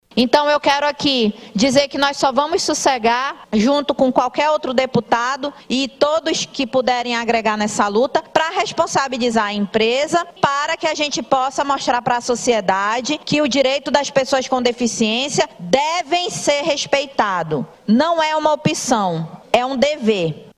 De acordo com a Deputada Estadual, Joana Darc, a loja precisa ser responsabilizada pela briga entre os clientes e por não ter cumprido a legislação estadual. Ouça o que diz a Deputada: